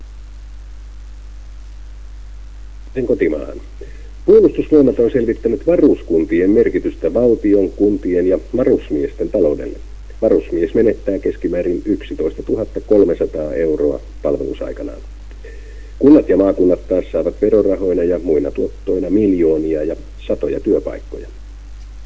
YLE-TV1:n uutiset: keskeinen osa äänitiedostona tässä